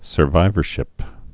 (sər-vīvər-shĭp)